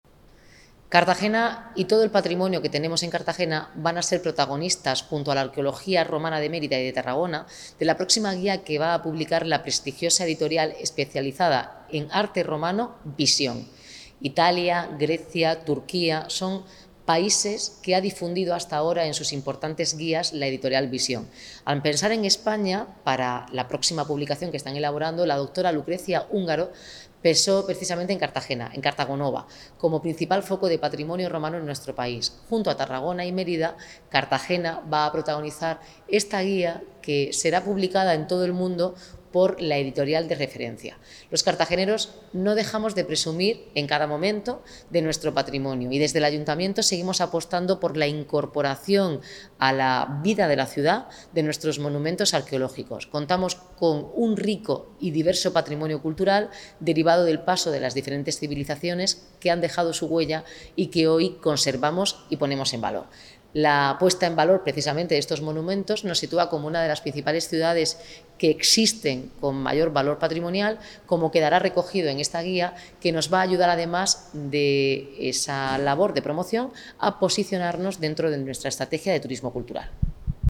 Enlace a Declaraciones Noelia Arroyo